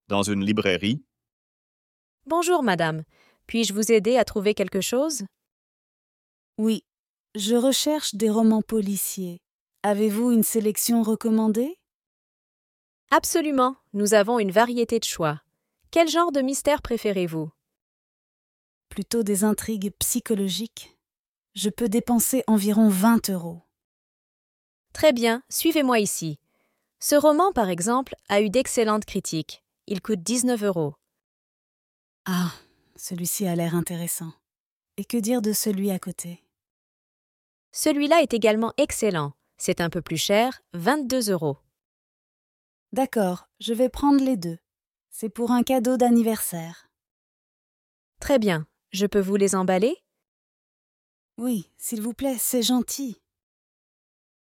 Dialogue FLE dans une librairie
Dialogue-FLE-dans-une-librairie.mp3